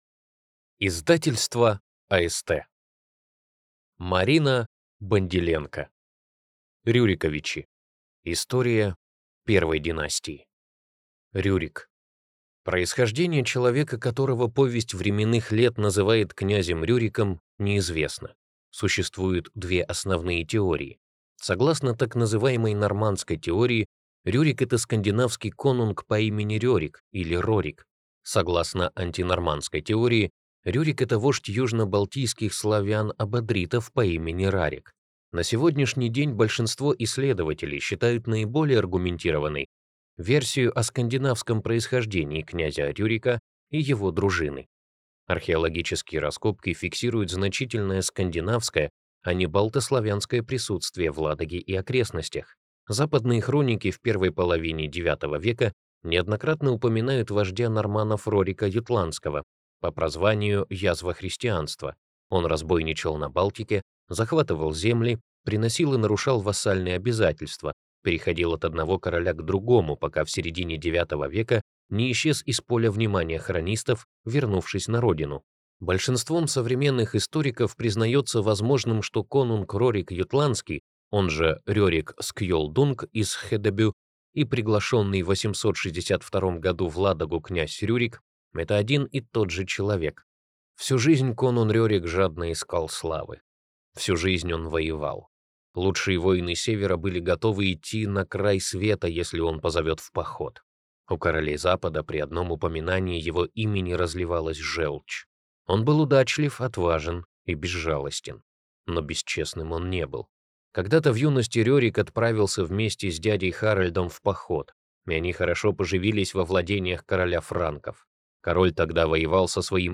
Аудиокнига Рюриковичи. История первой династии | Библиотека аудиокниг